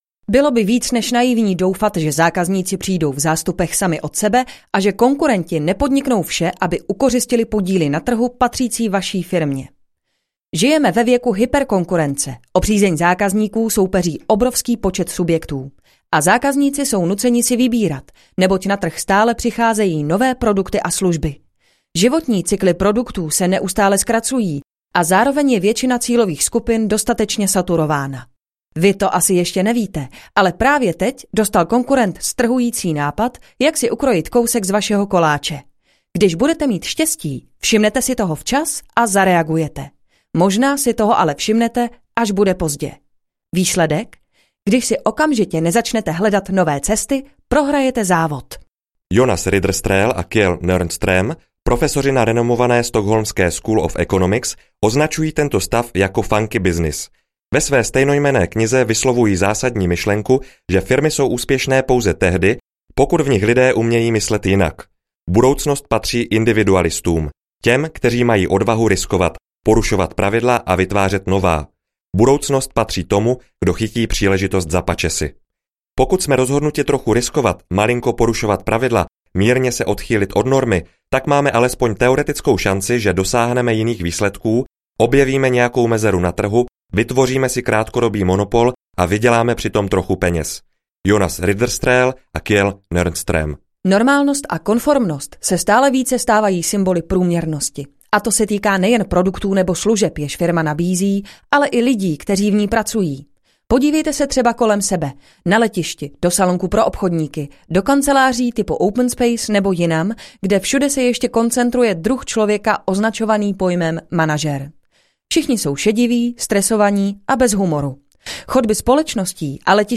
Nekonvenční myšlení audiokniha
Ukázka z knihy